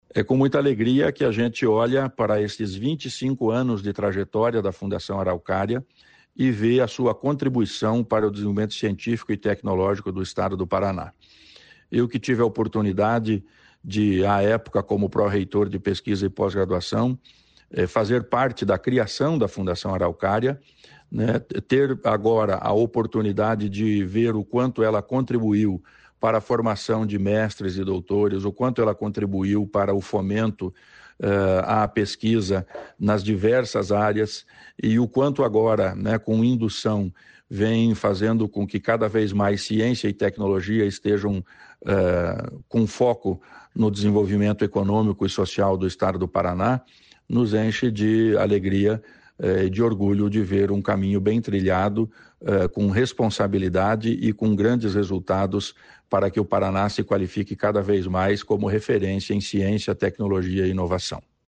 Sonora do secretário da Ciência, Tecnologia e Ensino Superior, Aldo Bona, sobre os 25 anos de Fundação Araucária